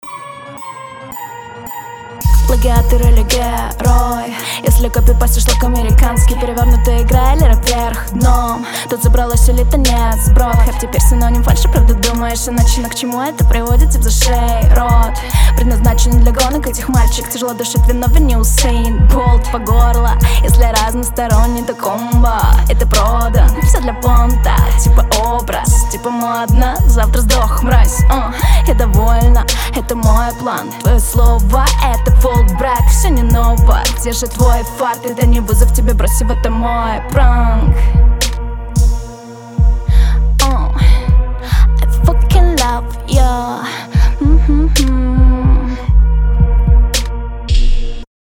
Чуть живей читай, что ли. Перебежки неплохие прям, радует, что ты при этом не "жуешь" слова, тебя более менее понятно. Но повторюсь - эмоциональней читай.
Стильно, приятно звучит, но текст не содержательный.
Не тяни так слова.